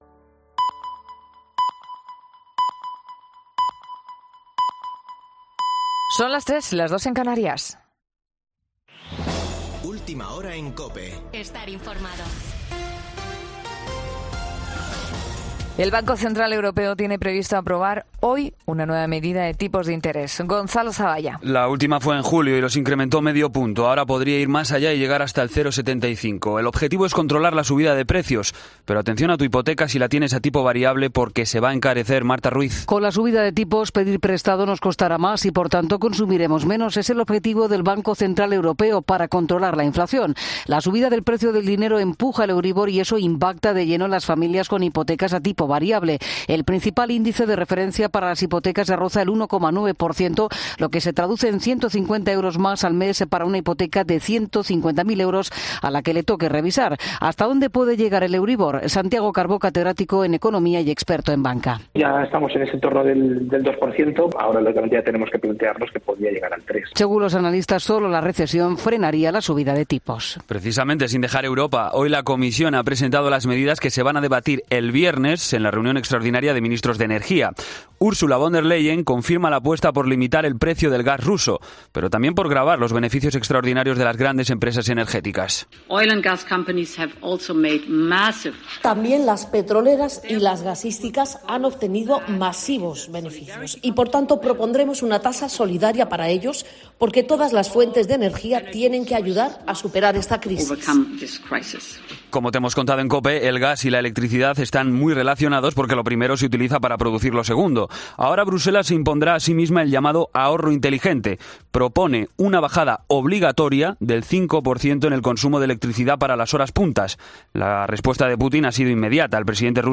Boletín de noticias COPE del 8 de septiembre a las 03:00 horas
AUDIO: Actualización de noticias Herrera en COPE